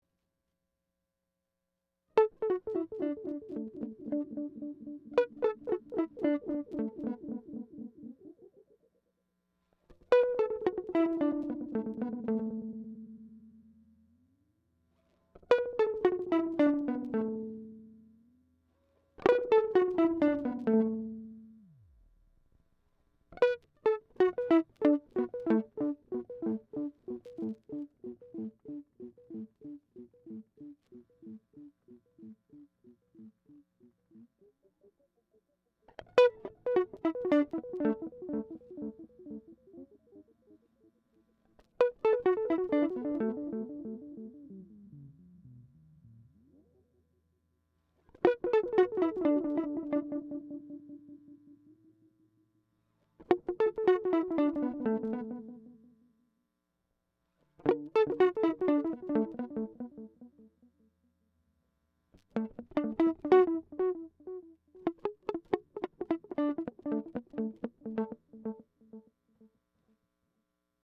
The Mu-Tron ECHO-TRON is a digital delay with an analog vibe that emulates vintage tape echo units.
From slapback echo to longer, lush delays, the ECHO-TRON provides a transparent ambience that inspires the player with spatiality.
Echo-Trom Demo Noodle Clip
DelayNoodle.mp3